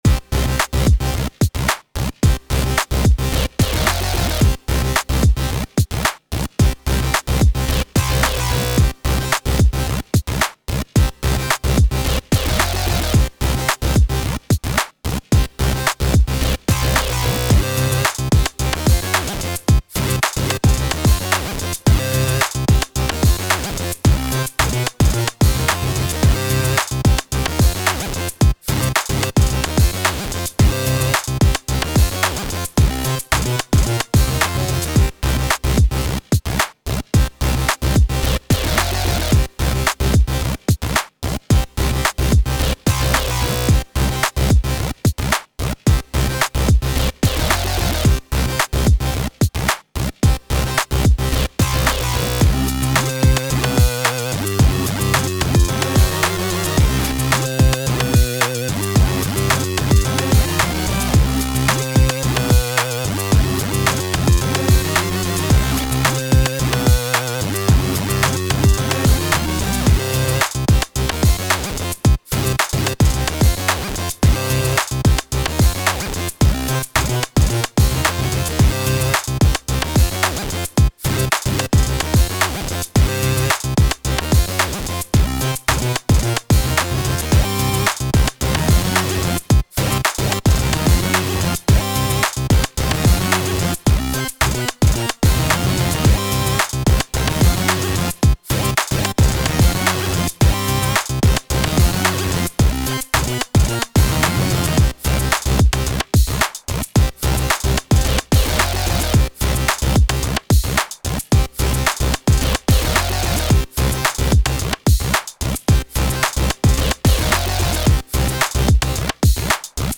タグ: Beat EDM ゲーム 電子音楽 コメント: 盗賊に遭遇したシーンをイメージしたゲームサウンド風の楽曲。